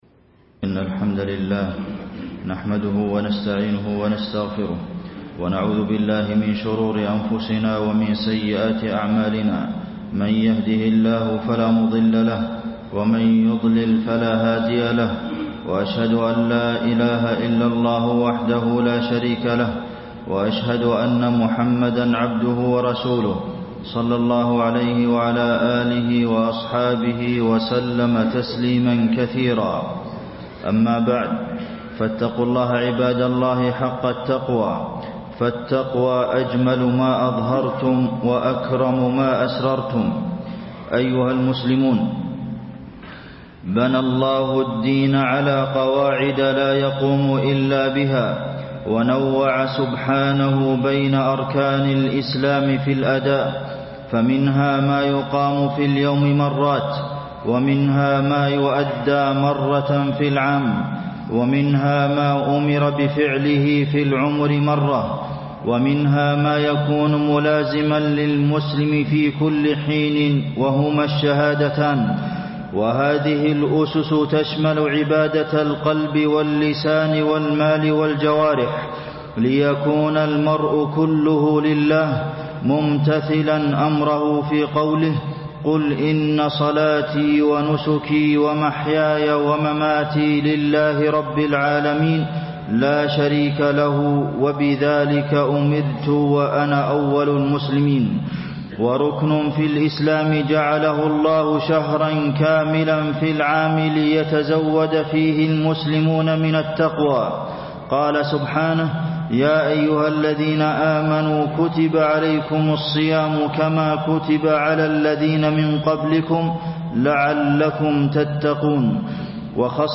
تاريخ النشر ٨ رمضان ١٤٣٣ هـ المكان: المسجد النبوي الشيخ: فضيلة الشيخ د. عبدالمحسن بن محمد القاسم فضيلة الشيخ د. عبدالمحسن بن محمد القاسم فضائل وفوائد الصيام